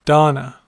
Ääntäminen
IPA : /ˈdɒn.ə/ US : IPA : /ˈdɑ.nə/